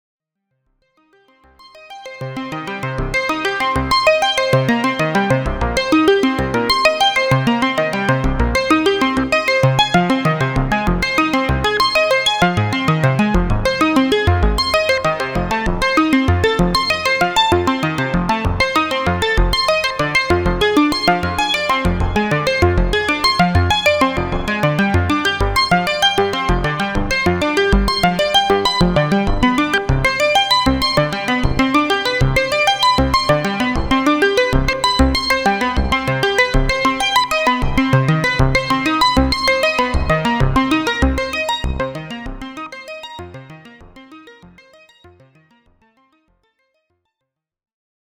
Everything was played right on the AstroLab, and there was no extra sound processing done.
Finally, here’s Arp Fast, a simple analog arpeggiator preset.
Arp-Fast.mp3